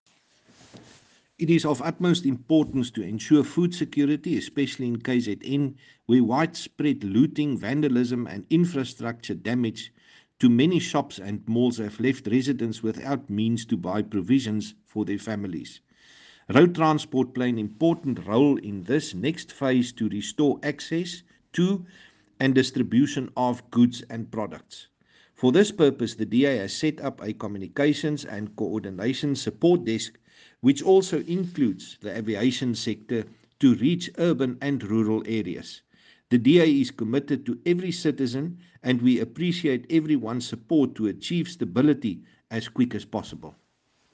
Issued by Chris Hunsinger MP – DA Shadow Minister of Transport
Afrikaans soundbites by Chris Hunsinger MP.